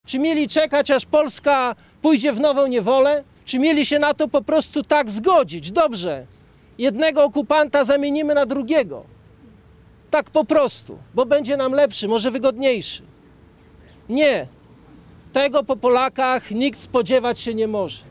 Jednocześnie pod obeliskiem Powstania Warszawskiego na olsztyńskim Zatorzu rozpoczęły się uroczystości upamiętniające wybuch powstania.
Przemawiając do zebranych poseł PiS Jerzy Szmit potępił tych, którzy podważają sens wybuchu powstania i docenił bohaterstwo Polaków sprzed 69 lat.